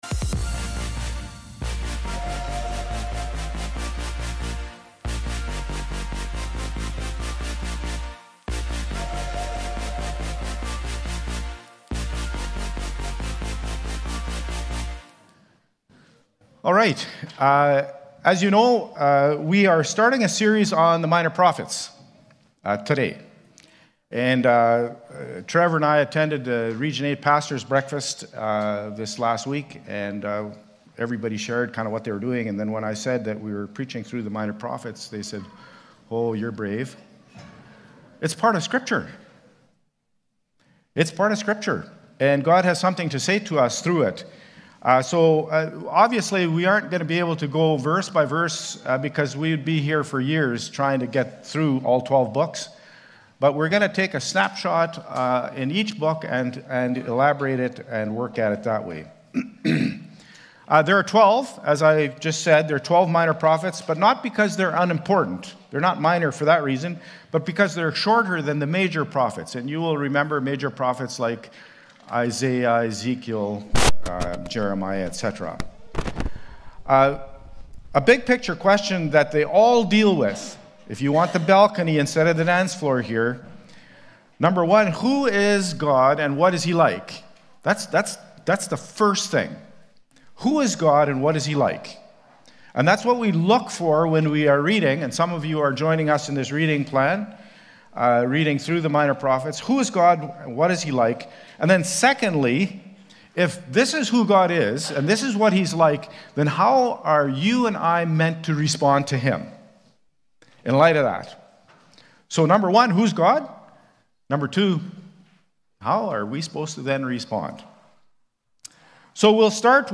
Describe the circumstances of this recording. January-12-worship-service.mp3